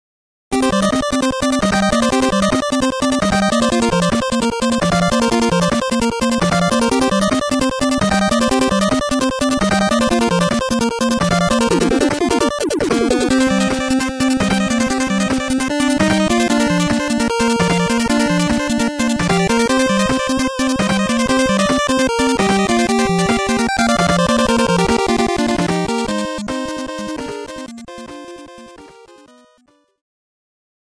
悪のロボット軍団を倒すアクションゲーム的な